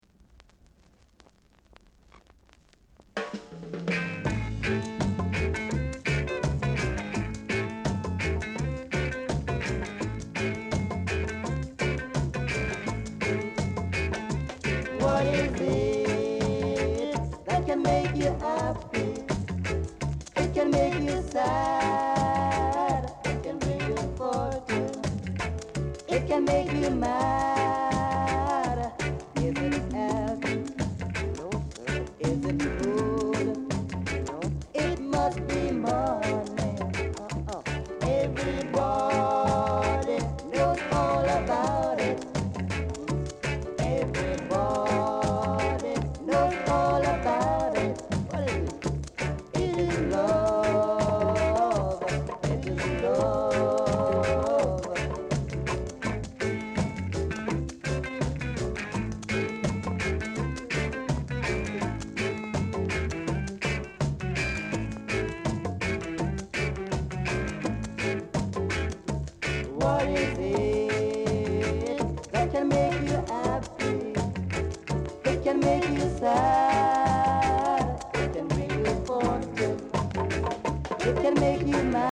R.Steady Vocal Group, Inst
Very rare! great rock steay vocal & inst!